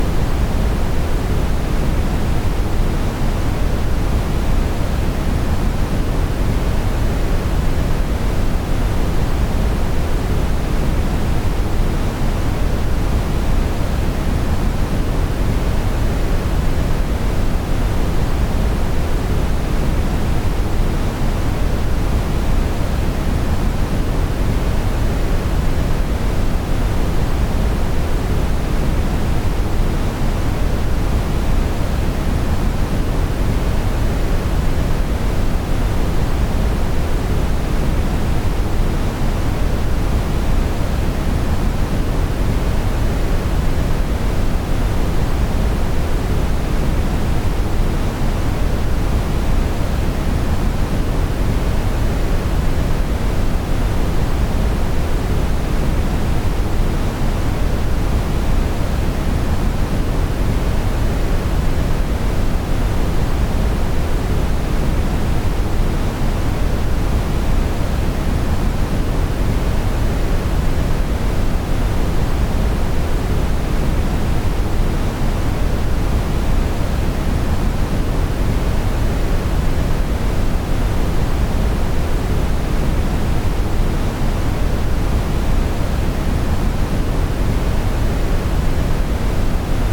Глубокие низкие частоты создают успокаивающий эффект, помогая отвлечься от стресса.
Коричневый шум 100 процентов